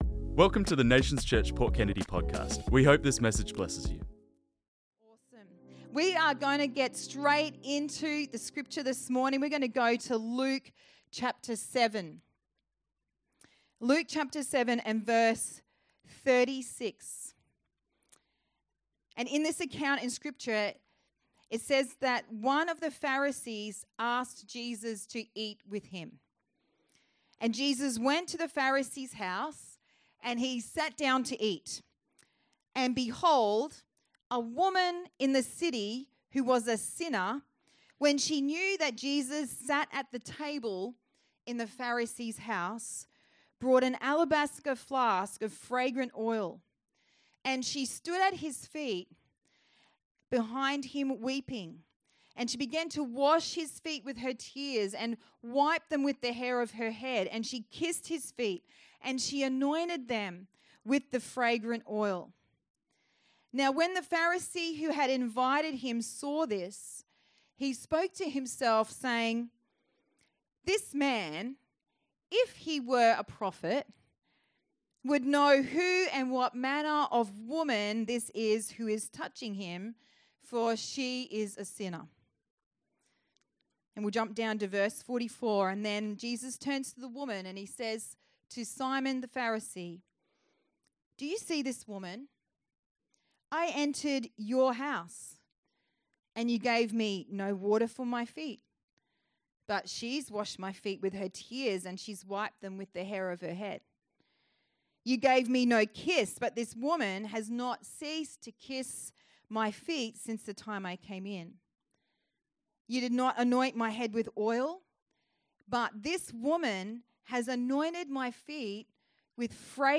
This message was preached on Sunday 29th June 2025